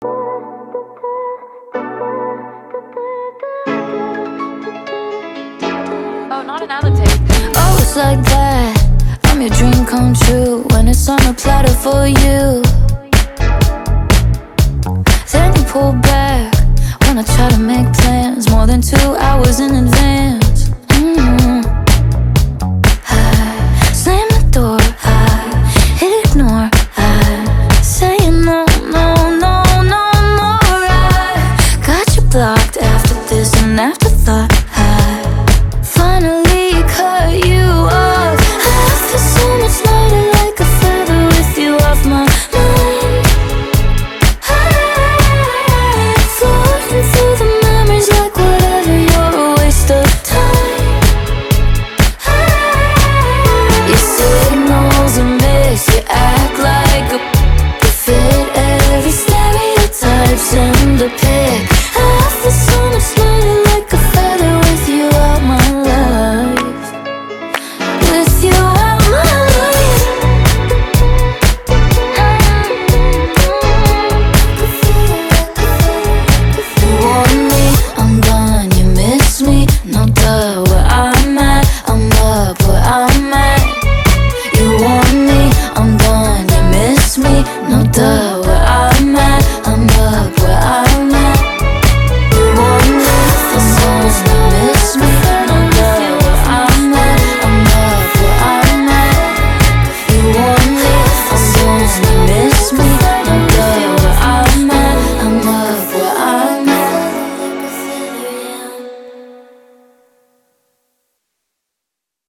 BPM124
Audio QualityPerfect (Low Quality)